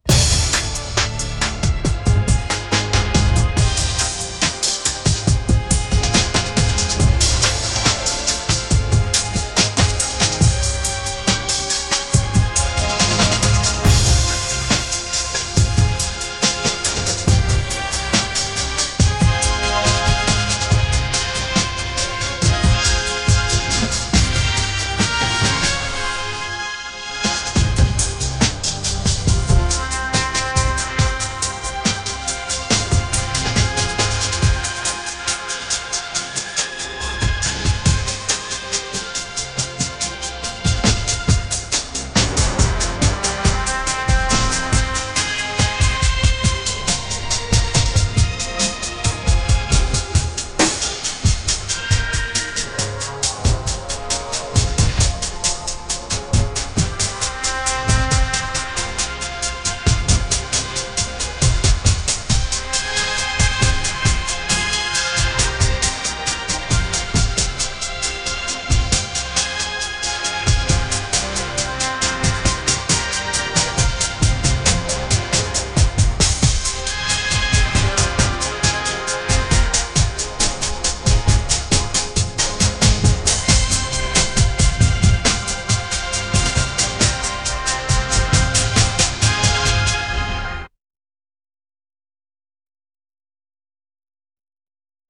music
action game theme_4.wav